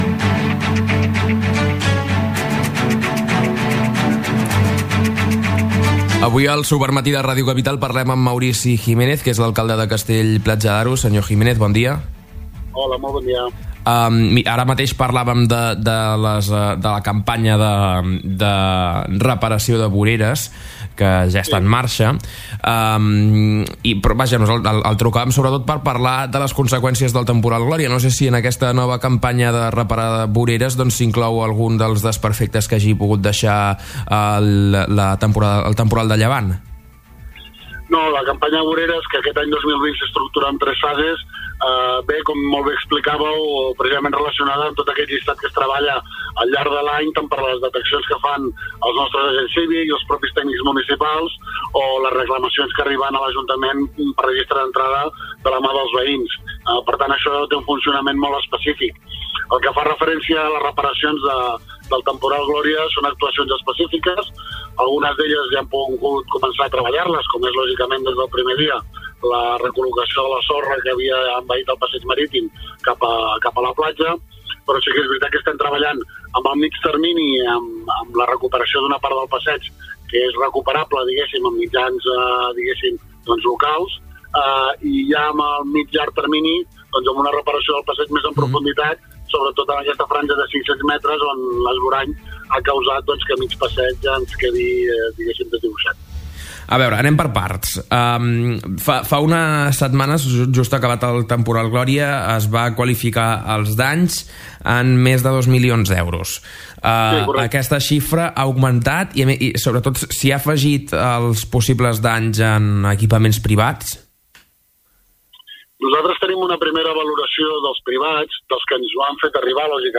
L’alcalde de Castell-Platja d’Aro, Maurici Jiménez, ha repassat en una entrevista al Supermatí els principals temes d’actualitat del municipi platjarenc.
Entrevista-Maurici-Jiménez.mp3